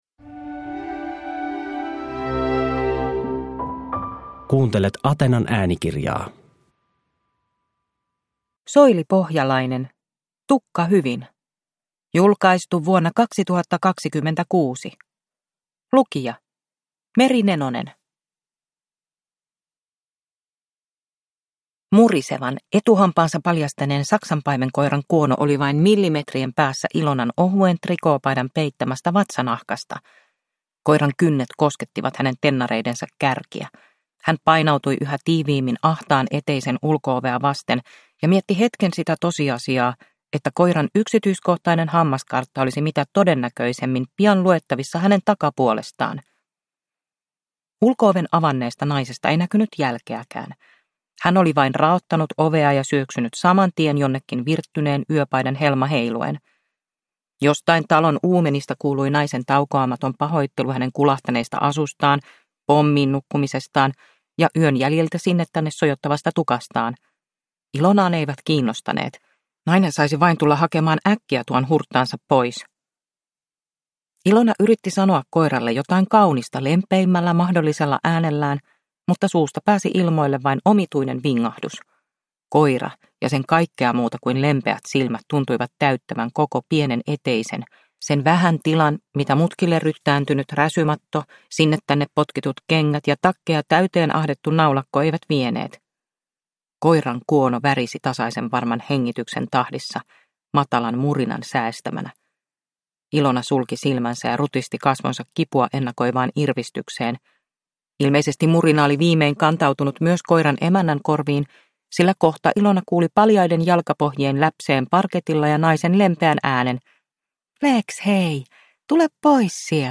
Tukka hyvin – Ljudbok